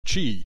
click this icon to hear the preceding term pronounced in Chinese).